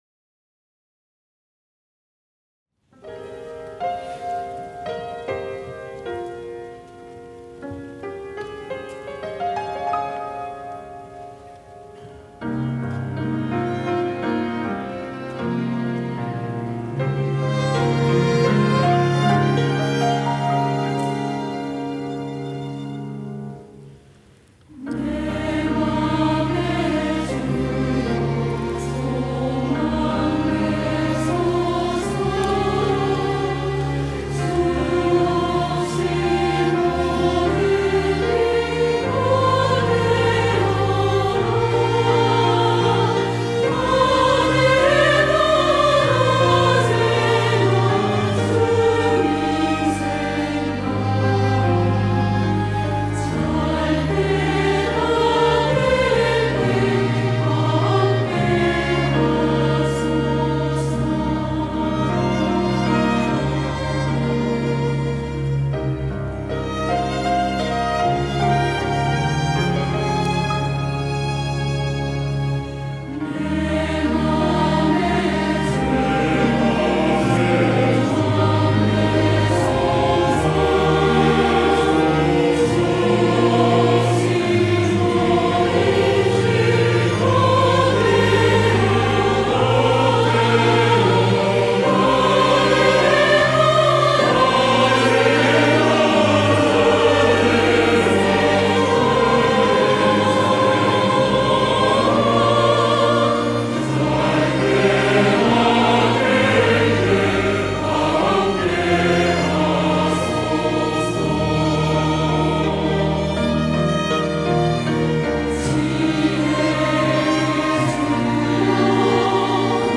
호산나(주일3부) - 내 맘의 주여 소망 되소서
찬양대